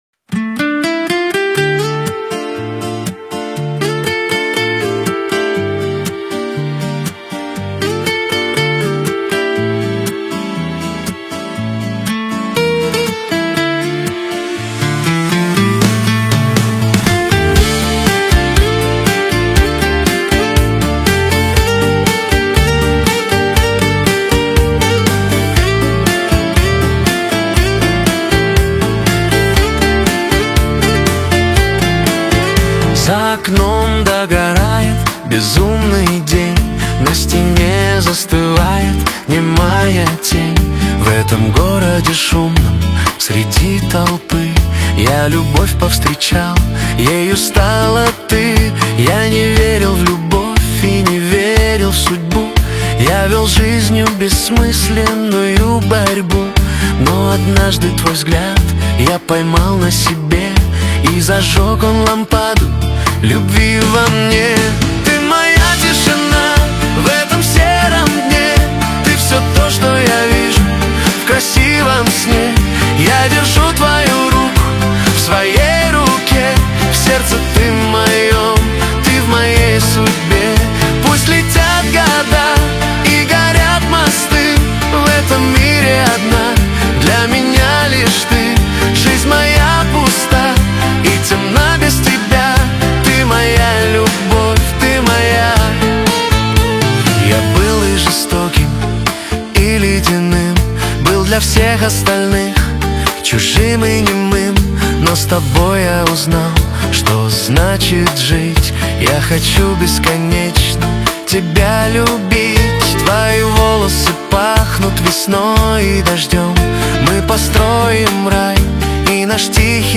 Лирика
диско